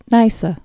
(nīsə)